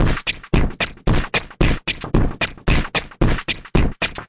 126 bpm循环" 126 FX Flowd G A
描述：处理循环FX
Tag: 嘈杂 126bpm 混响 PS ychedelic 慢慢跑偏 撞击 加工 FX